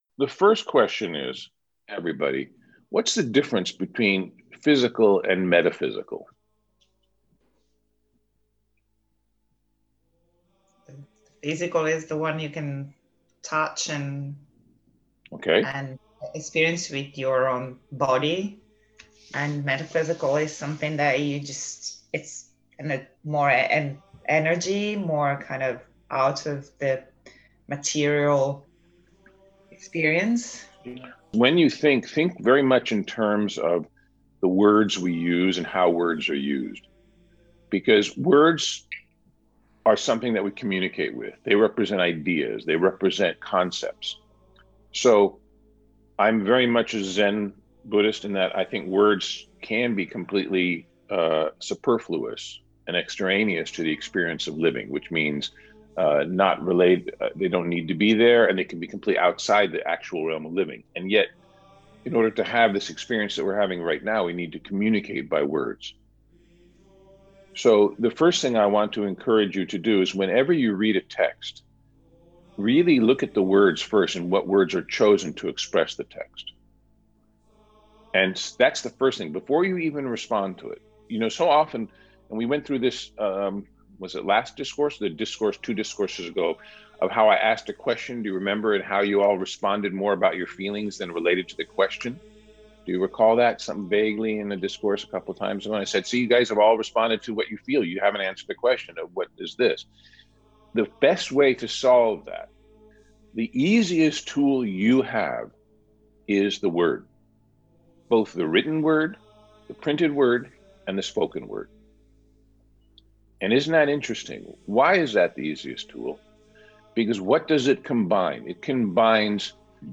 Path to Wisdom :: Conversation
We will discuss how we act what power we have what power we think we have and do we really have any power at all? Join us for a dynamic and interesting discussion referencing many different philosophical approaches.